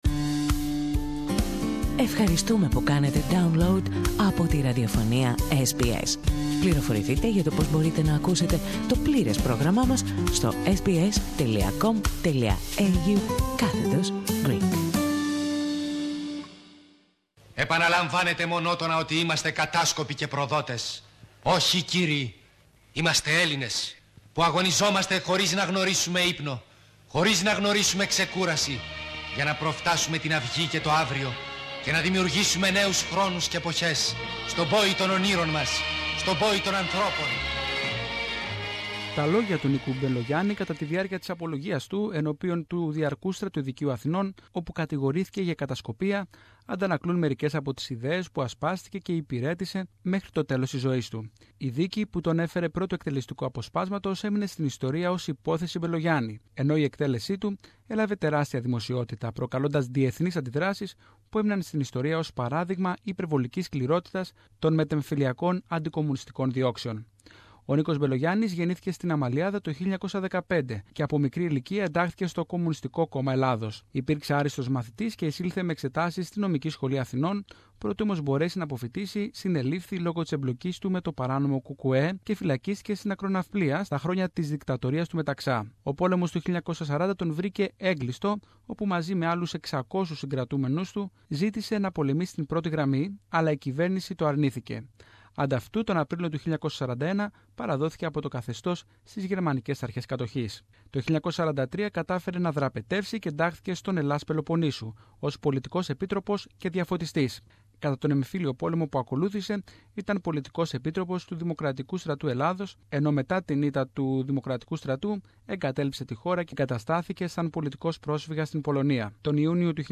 (Για το αφιέρωμα χρησιμοποιήθηκαν συνεντεύξεις από την εκπομπή της ΕΡΤ, Ρεπορτάζ Χωρίς Σύνορα καθώς και αποσπάσματα από την ταινία, ο Άνθρωπος με το Γαρύφαλλο, του Νίκου Τζίμα.)